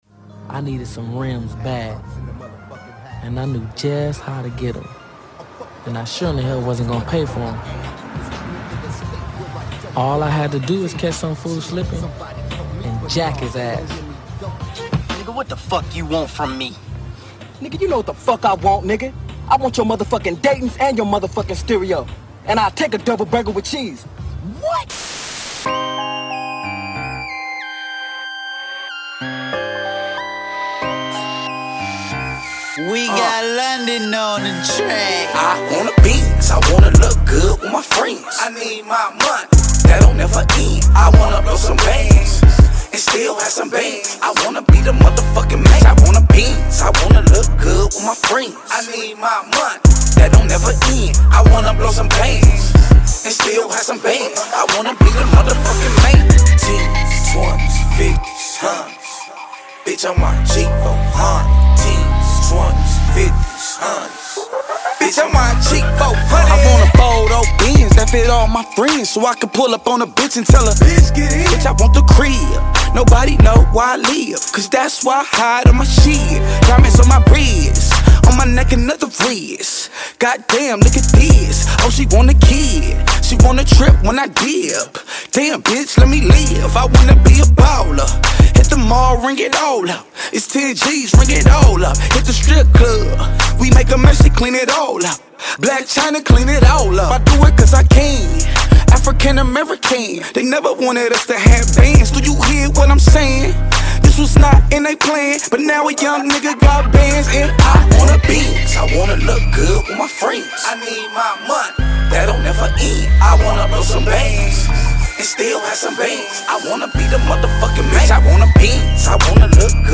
DROPS HIS NEW WEST COAST BANGER